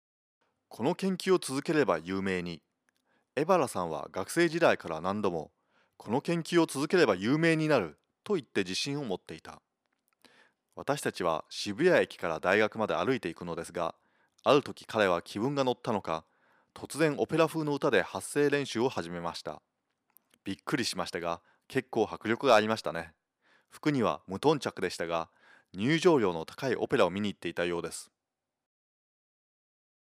Sprecher japanisch, Profisprecher, für Werbung und Industrie
Kein Dialekt
Sprechprobe: Werbung (Muttersprache):